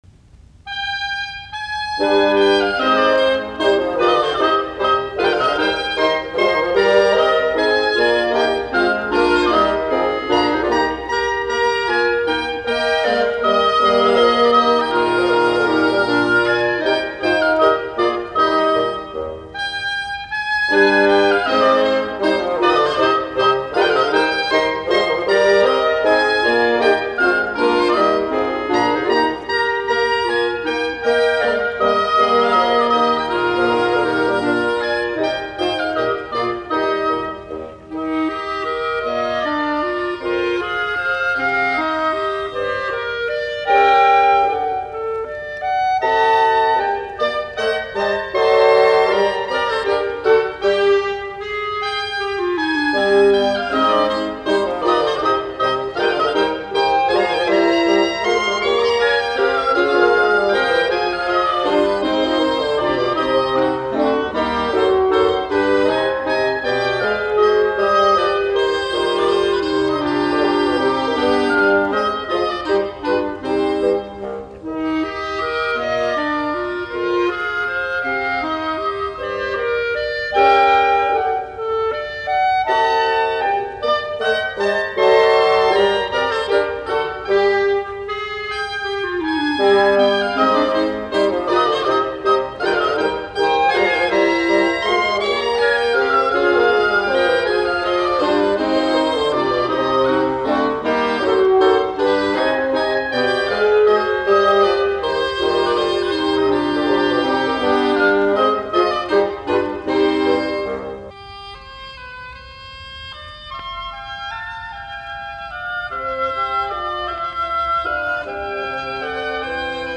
Serenadit, puhaltimet, KV384a = KV388, c-molli